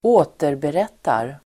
Ladda ner uttalet
återberätta verb, retell Grammatikkommentar: A & x Uttal: [²'å:terberet:ar] Böjningar: återberättade, återberättat, återberätta, återberättar Synonymer: skildra Definition: berätta i andra hand, återge, relatera